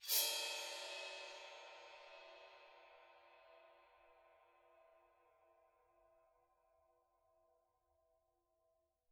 Percussion
susCymb1-scrape2_v1.wav